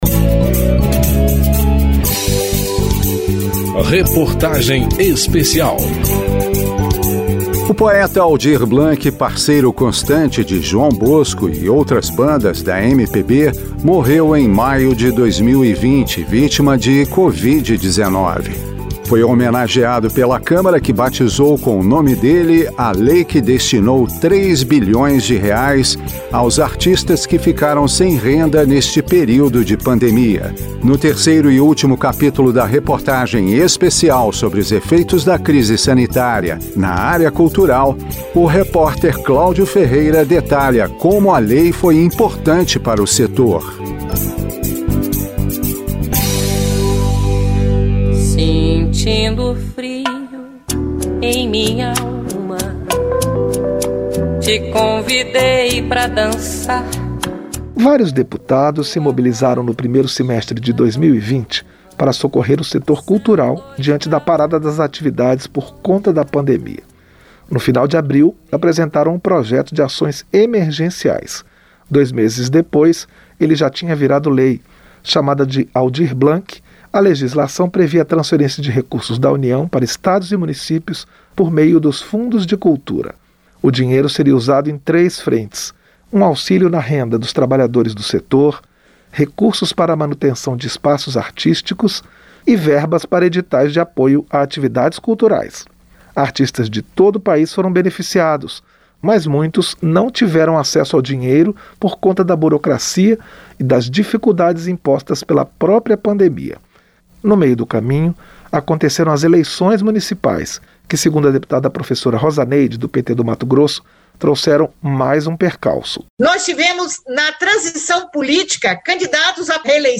Reportagem Especial